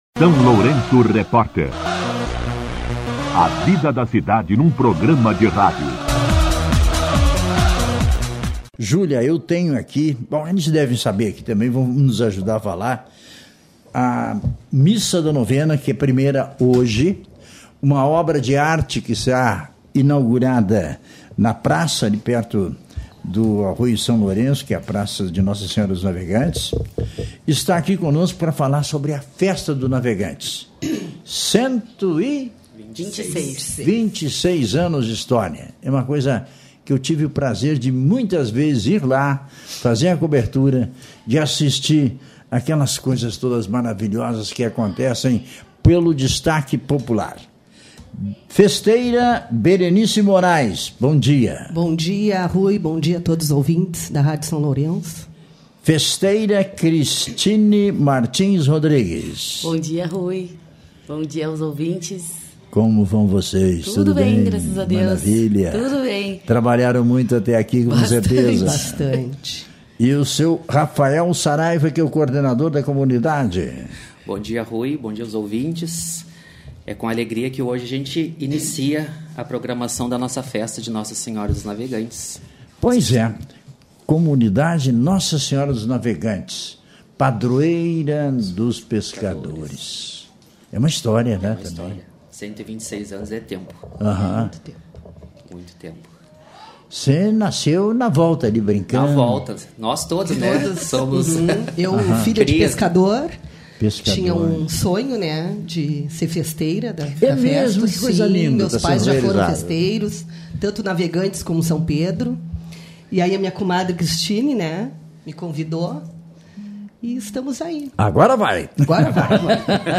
As festeiras participaram da entrevista representando os casais.